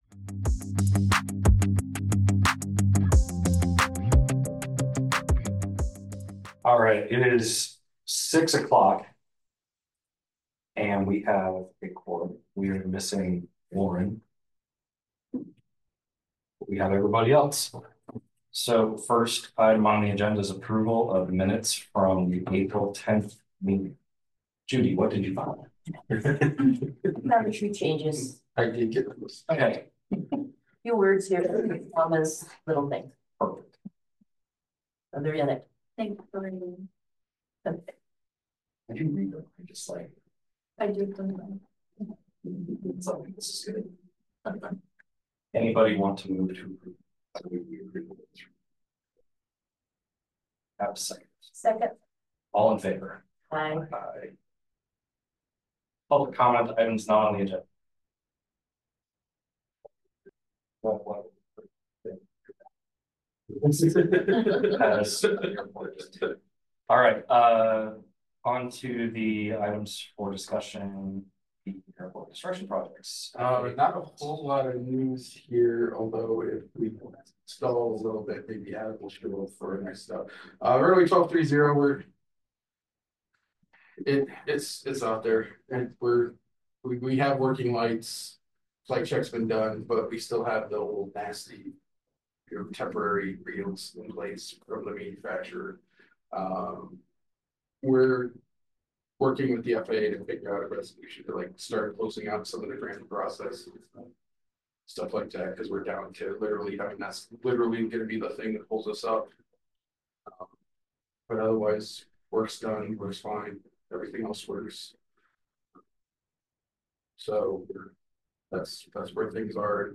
Regular monthly meeting of the City of Iowa City's Airport Commission.